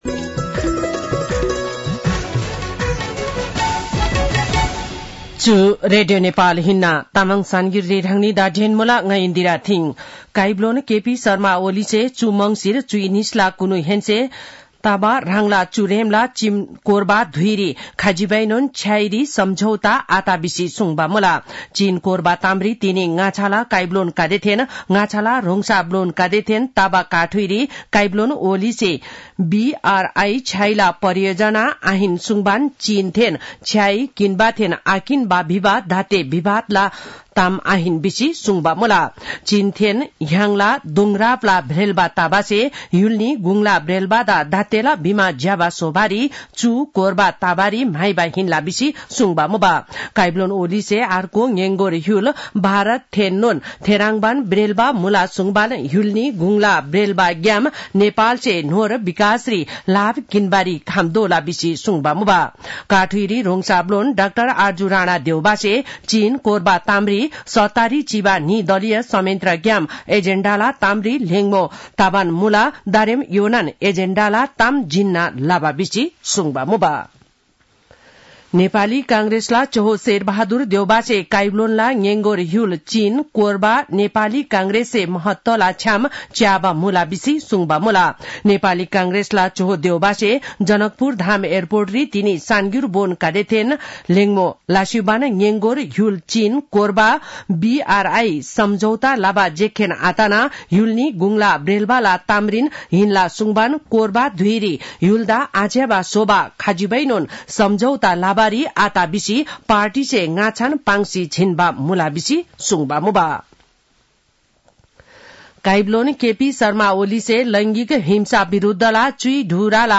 तामाङ भाषाको समाचार : ११ मंसिर , २०८१